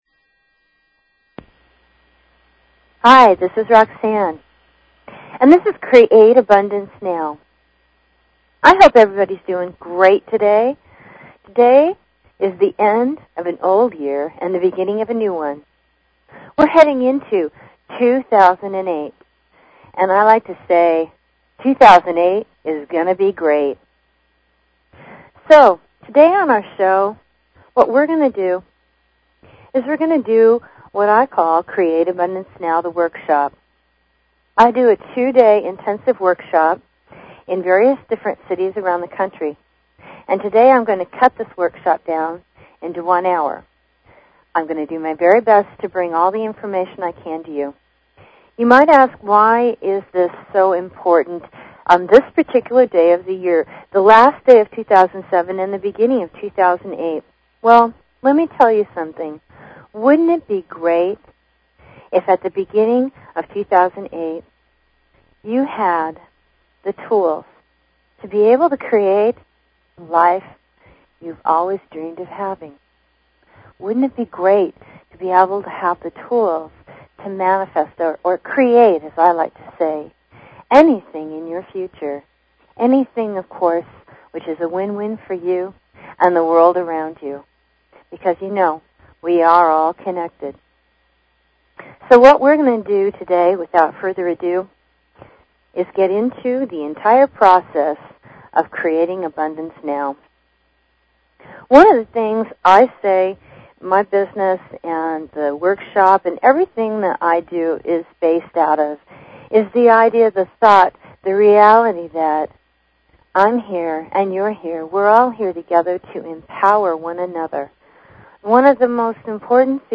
Talk Show Episode, Audio Podcast, Create_Abundance_Now and Courtesy of BBS Radio on , show guests , about , categorized as